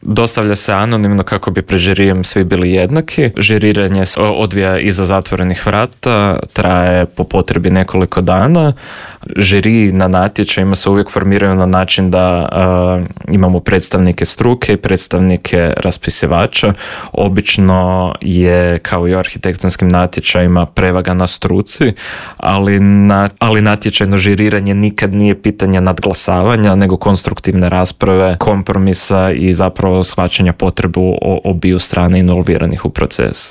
U današnjem intervjuu MS